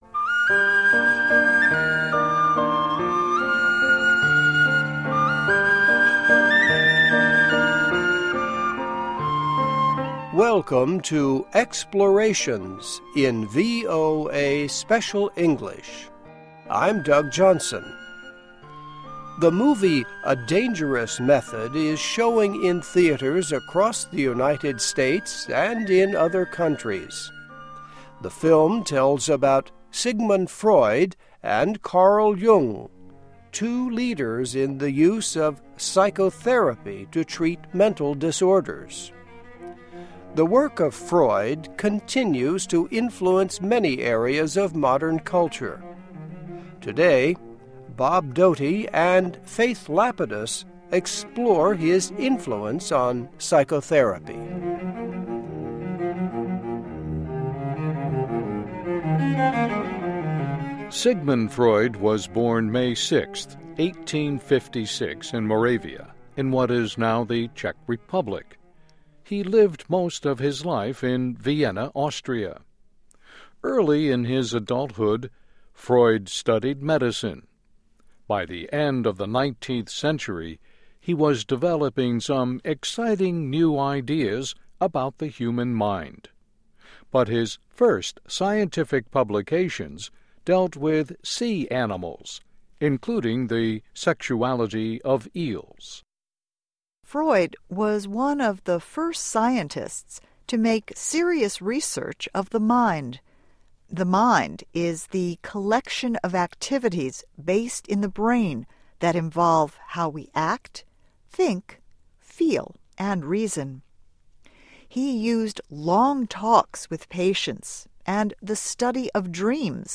Welcome to Explorations in VOA Special English.